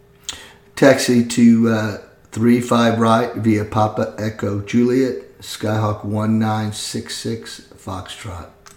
Aviation Radio Calls
08a_PilotRunwayThreeFiveRightViaPapaEchoJulietResponse.mp3